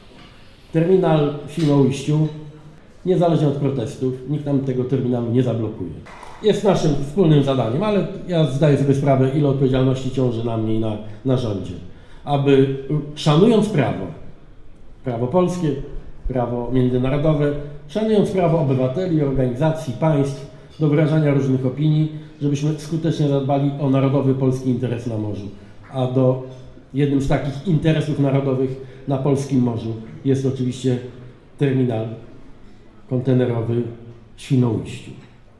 Premier odniósł się bezpośrednio do kontrowersji wokół planowanej inwestycji w Świnoujściu: